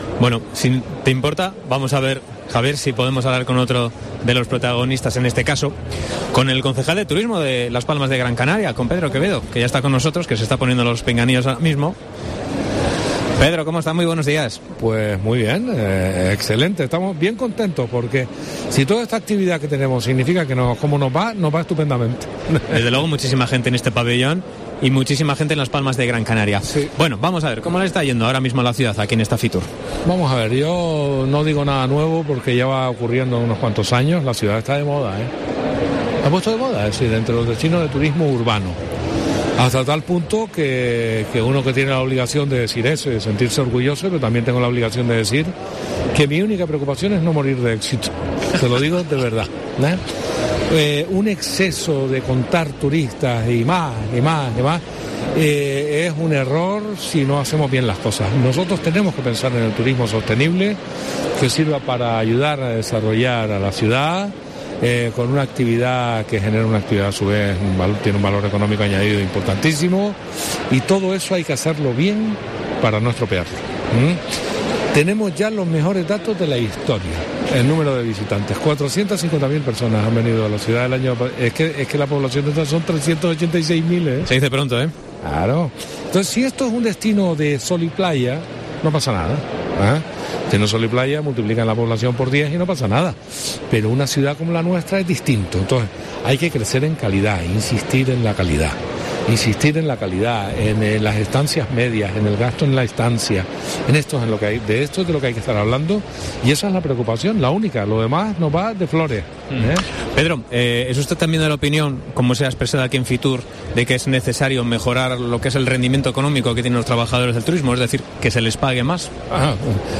Entrevista a Pedro Quevedo, concejal de Turismo en Las Palmas de Gran Canaria, en FITUR